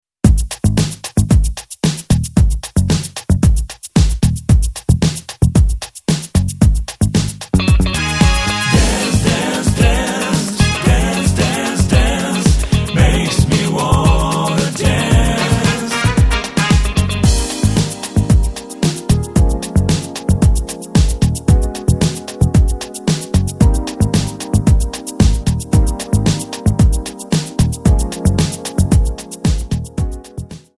MPEG 1 Layer 3 (Stereo)
Backing track Karaoke
Pop, 2010s